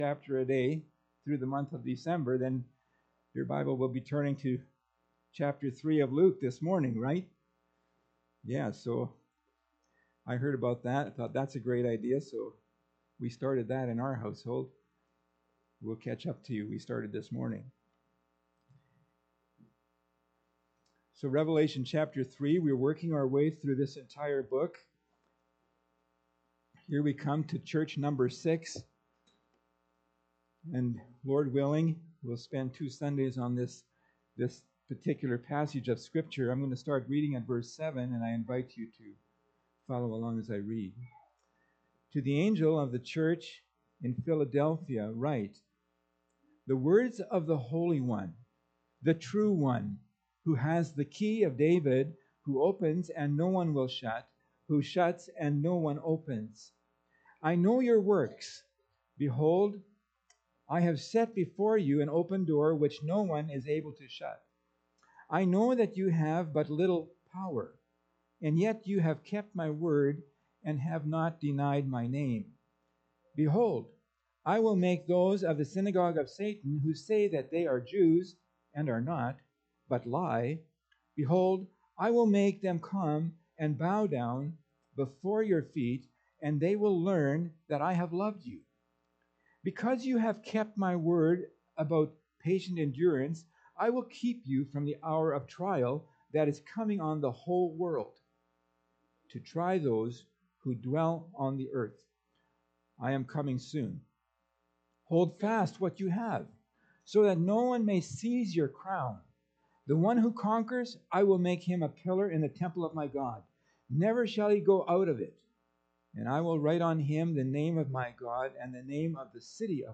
Pulpit Sermons Key Passage: Revelation 3:7-13 https